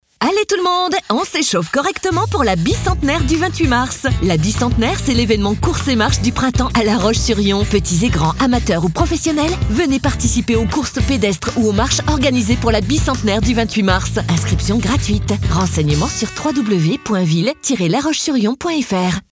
Mairies, Communautés de Communes ou Union des Commerçants, nous avons réalisé pour eux les spots publicitaires qu'ils souhaitaient !